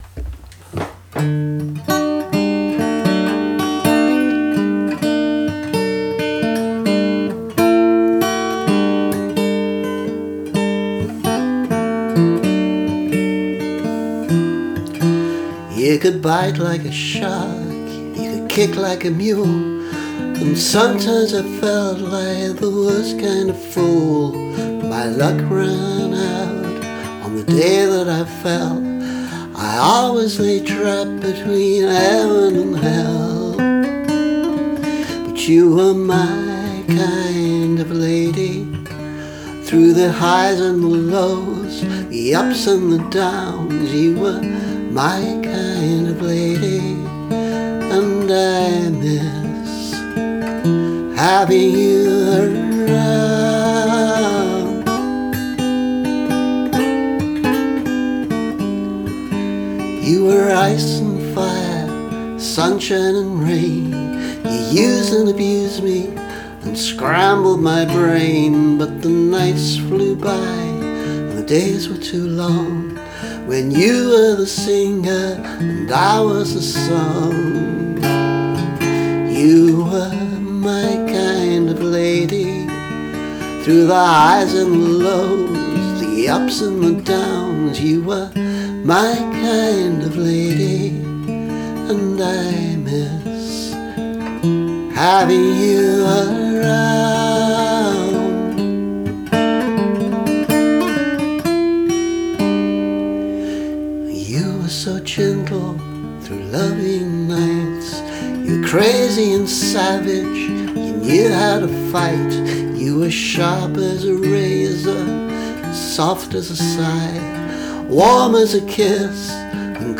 My kind of lady [demo]